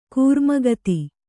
♪ kūrma gati